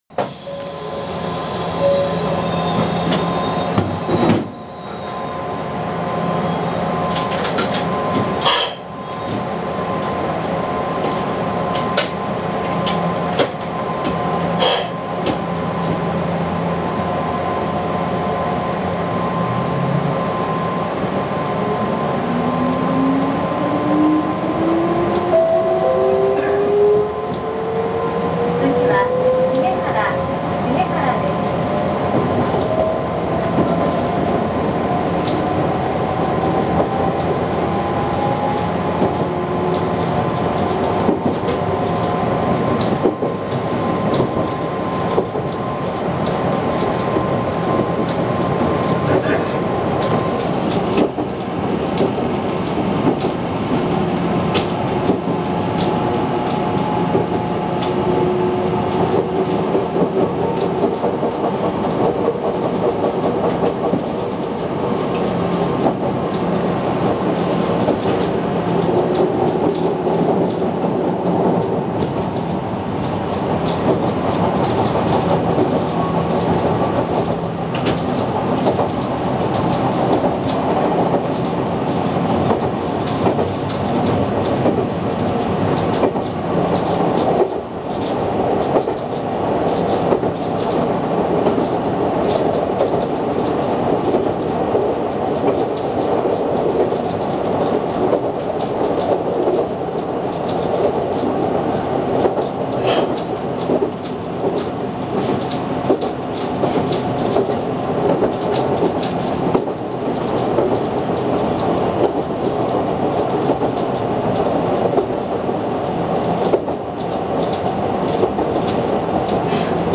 ・7700系走行音
【三河線】刈谷〜重原（2分42秒：1.24MB）…7715Fにて
まさかのドアチャイム搭載なことを除けばごく普通の走行音。
別段、走行中静かだとかいうこともありませんが、モーター音は目立ちません。